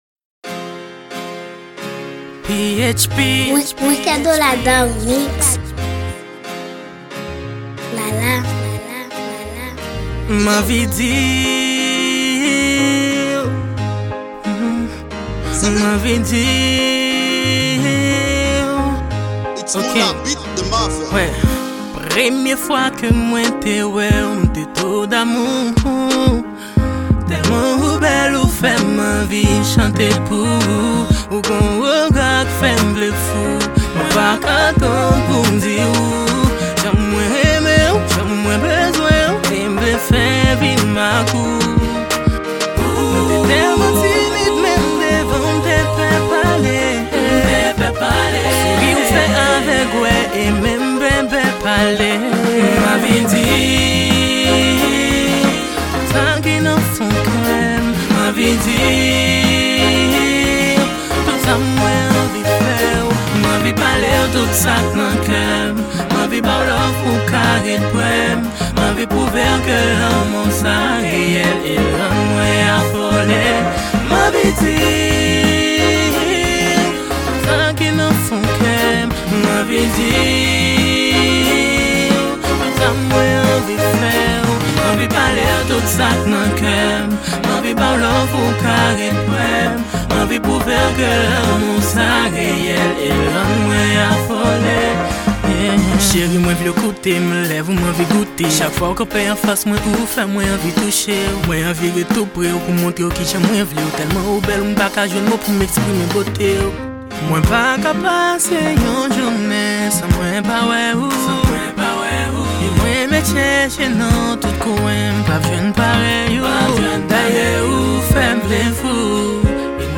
Genre: RAAP.